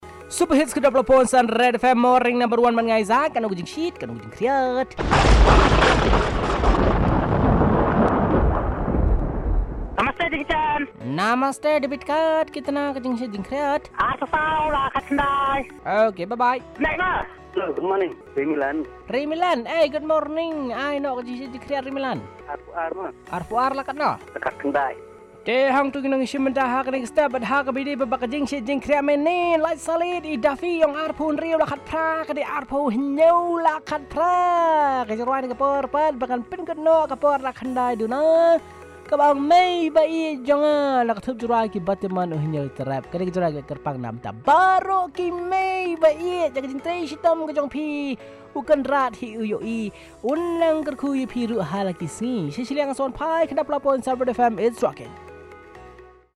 Callers on Temperature Predictions